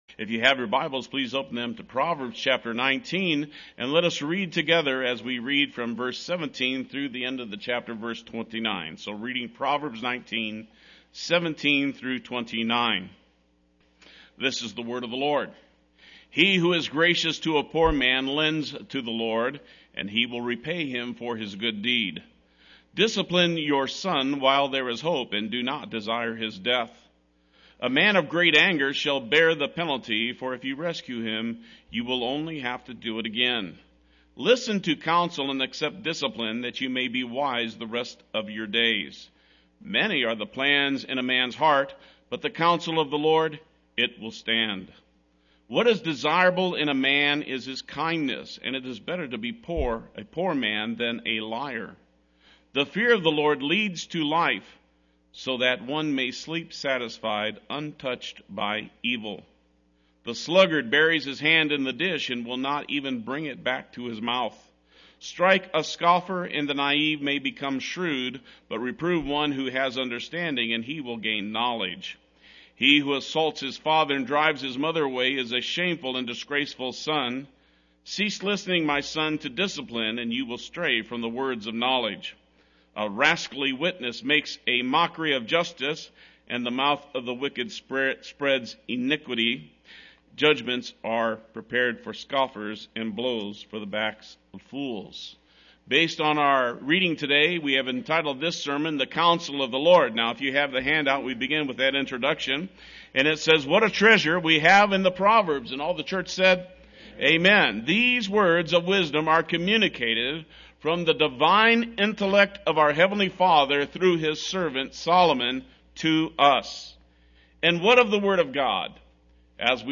Play Sermon Get HCF Teaching Automatically.
“The Counsel of the Lord” Sunday Worship